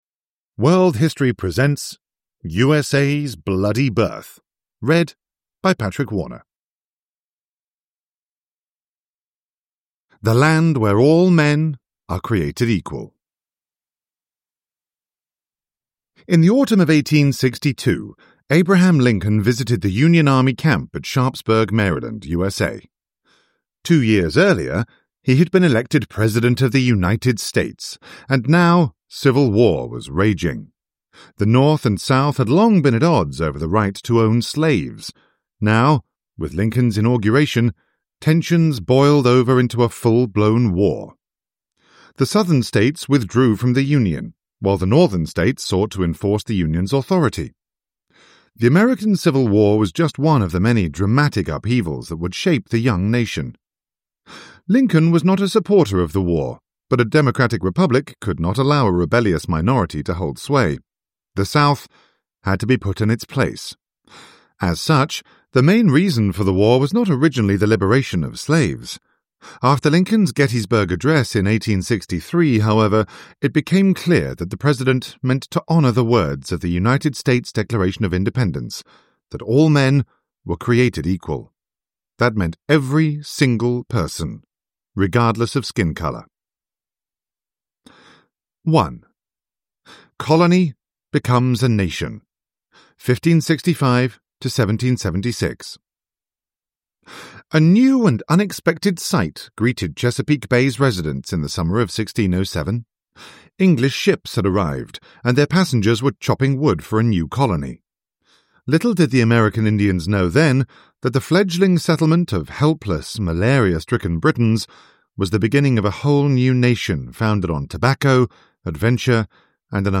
The USA's Bloody Birth – Ljudbok